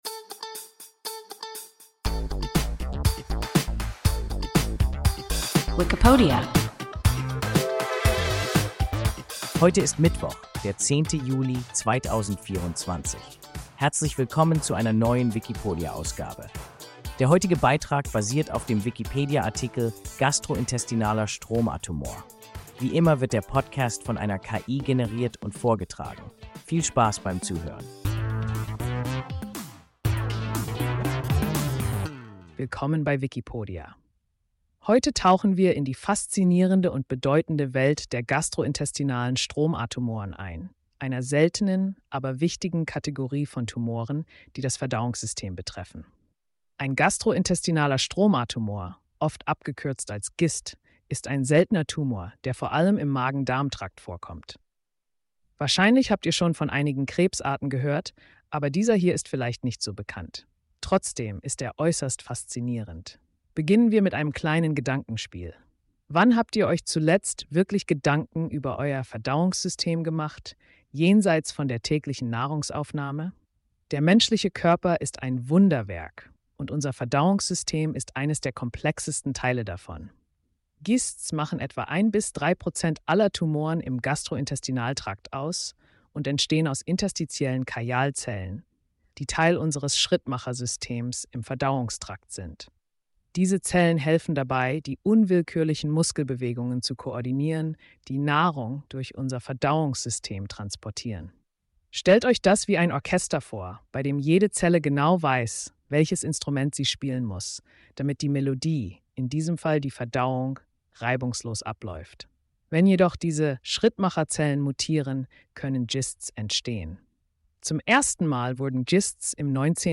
Gastrointestinaler Stromatumor – WIKIPODIA – ein KI Podcast